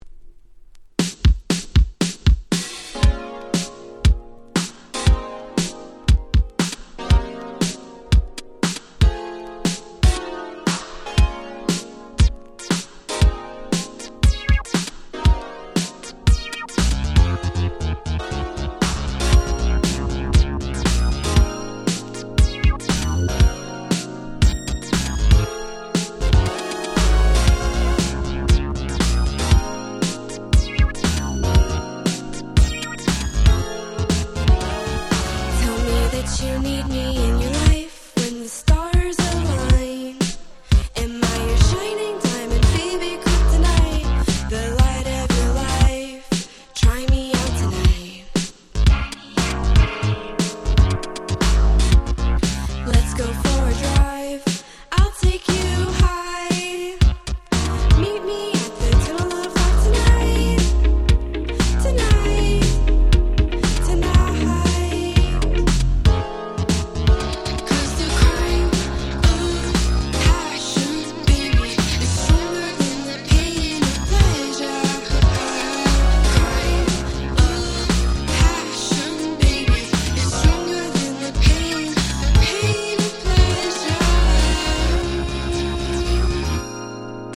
切ないメロディーがたまらない哀愁Boogie